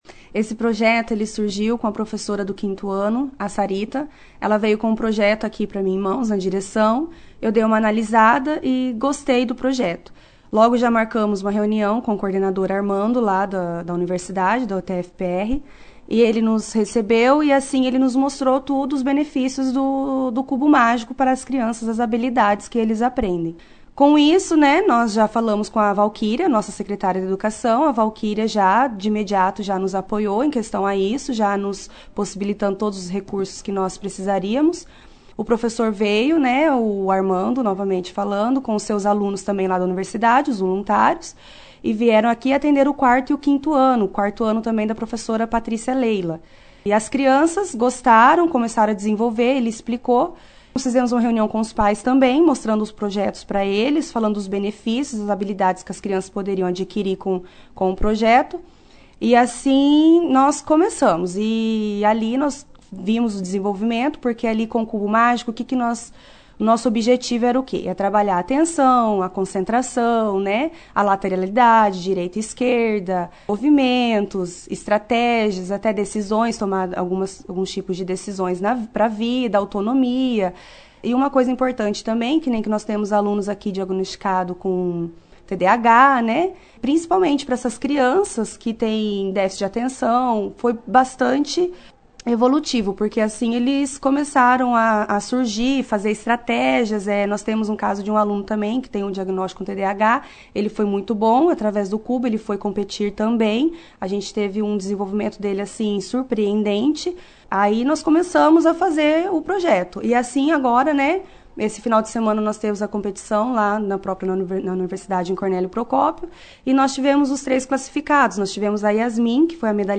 participou da 2ª edição do jornal Operação Cidade desta quinta-feira, 11/07, falando sobre o projeto